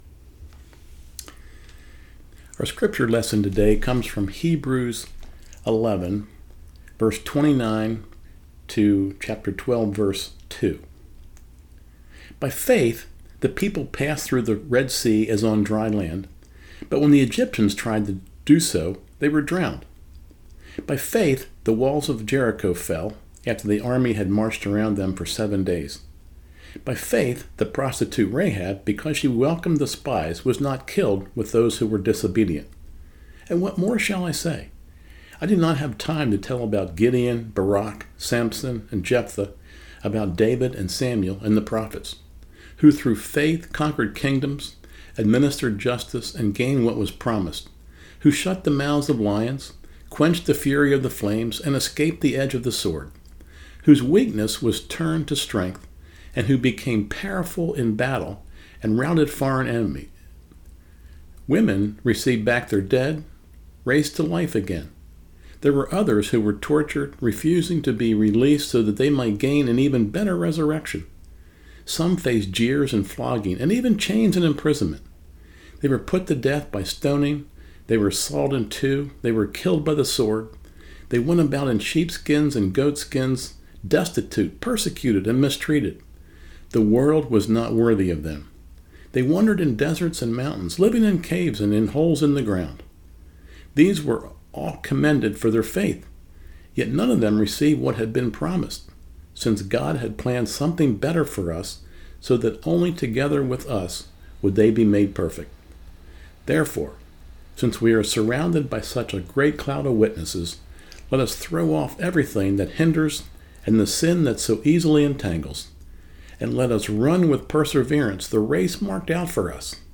Sermon Page
sermon-turnyoureyesuponjesus.mp3